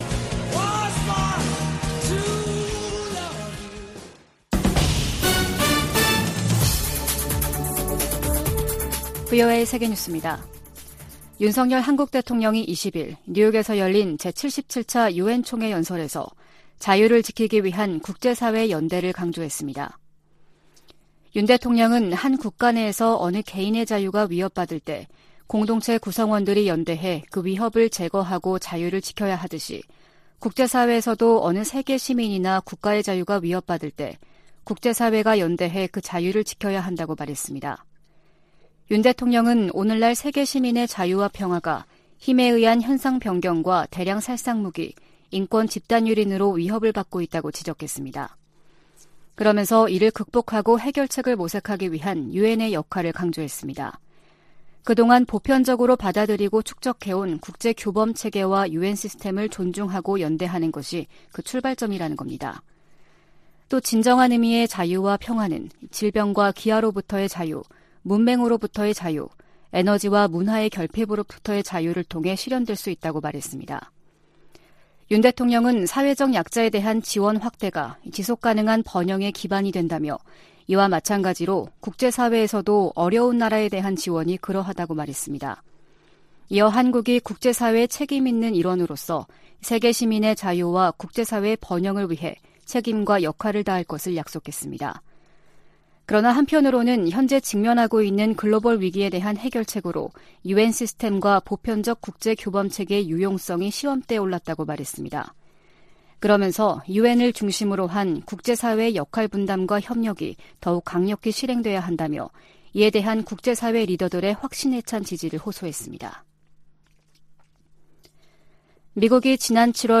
VOA 한국어 아침 뉴스 프로그램 '워싱턴 뉴스 광장' 2022년 9월 21일 방송입니다. 한국이 북한에 제안한 ‘담대한 구상’은 대화 초기부터 북한이 우려하는 체제안보와 정치, 군사적 문제를 논의할 수 있다는 취지라고 권영세 한국 통일부 장관이 말했습니다. 미 국방부가 향후 5년간 중국과 북한 등의 대량살상무기 위협 대응으로 억지, 예방, 압도적 우위의 중요성을 강조했습니다. 최근 중국 항구에 기항하는 북한 선박이 늘고 있는 것으로 나타났습니다.